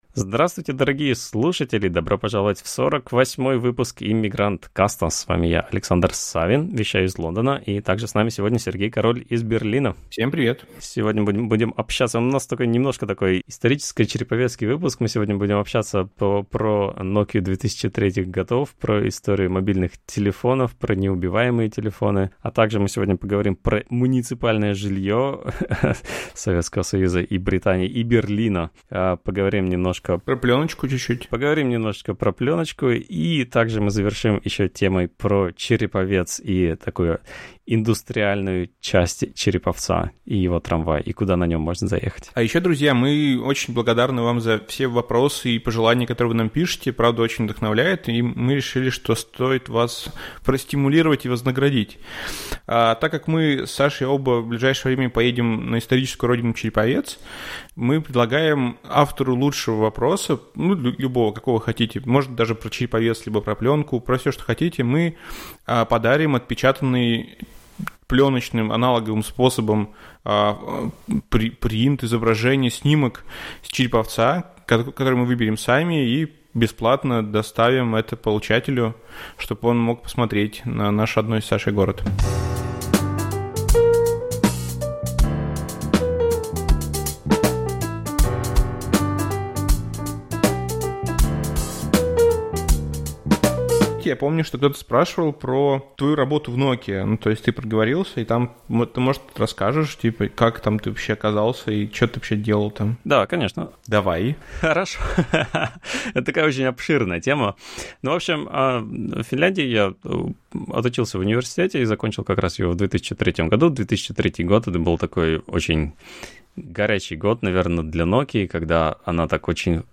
Подкаст о жизни в Лондоне, Берлине и Нью-Йорке, пленочной фотографии, инди-интернете, лодках, брекзите и дронах. На проводе гости от Сингапура и Гоа до Франции и США — от дизайнера шрифтов до пилота самолета.